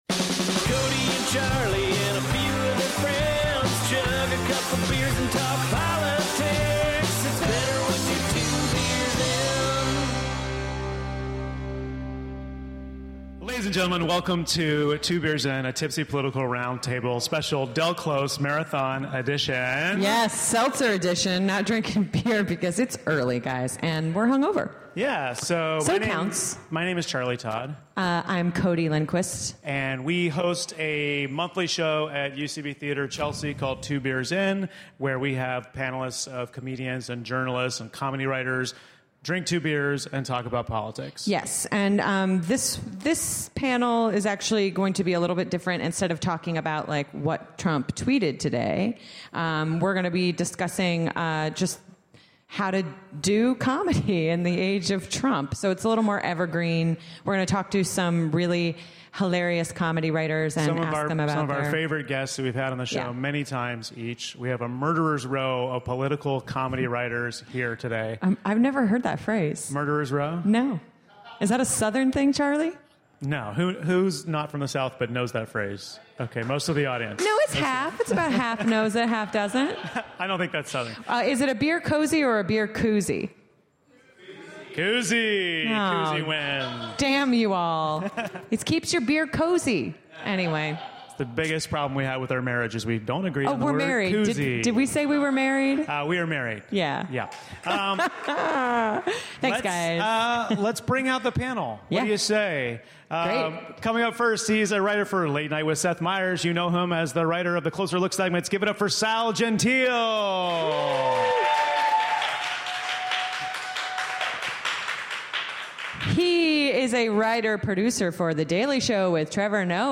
live from the Del Close Marathon in NYC.